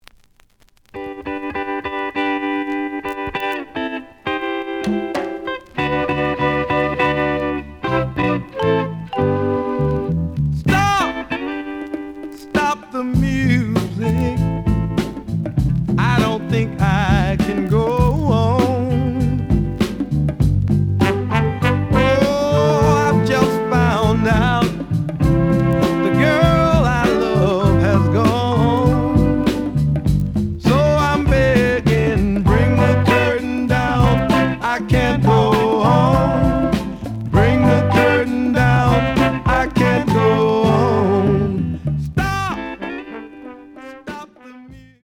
The audio sample is recorded from the actual item.
●Genre: Soul, 60's Soul
There is a bubble on beginning of B side, but almost good.)